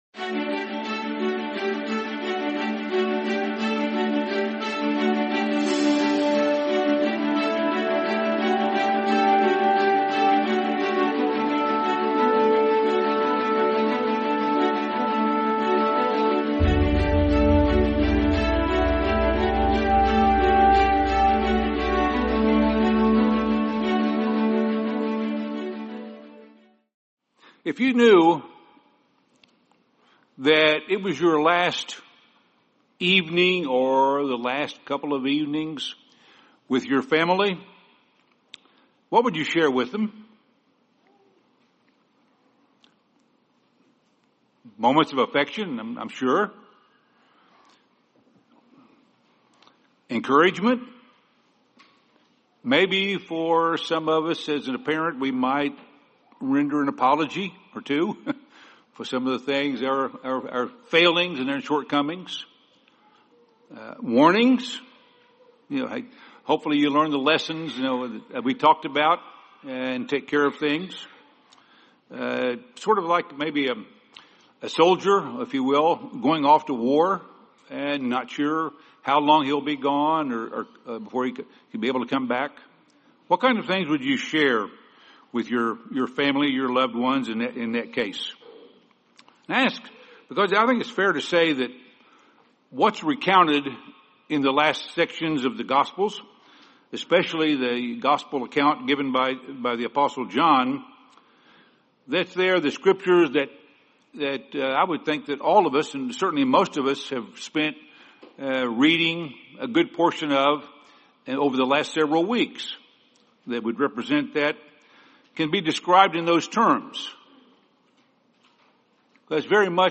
Takeaways from Christ's Last Passover | Sermon | LCG Members